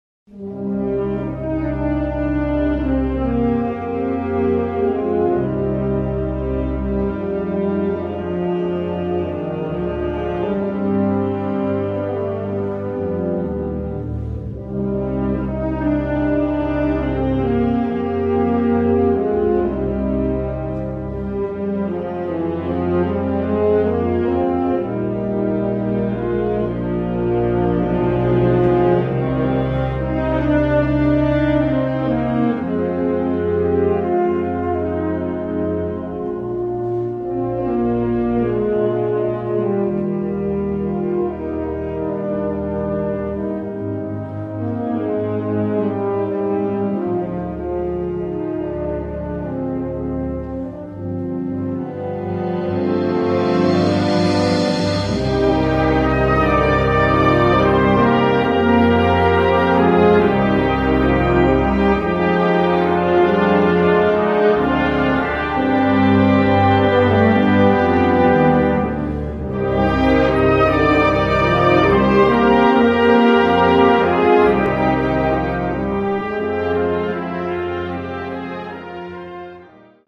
Rozrywkowa